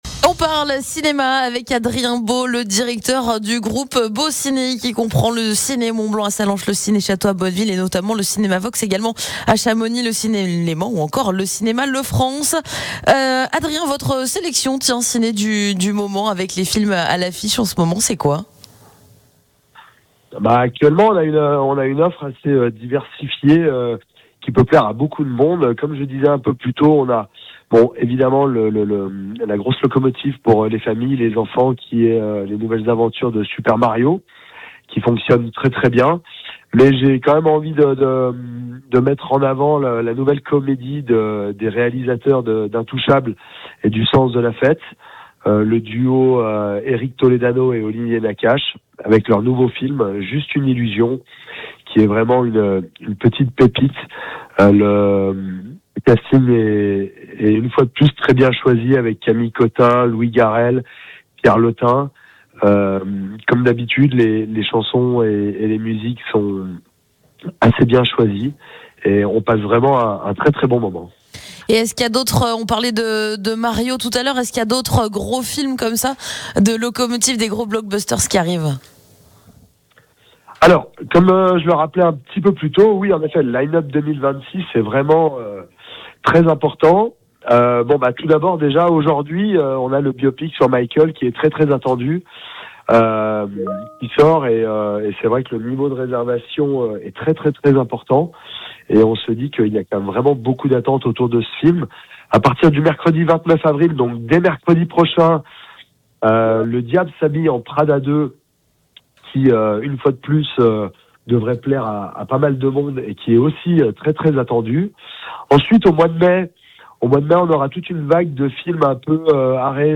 Interview part 2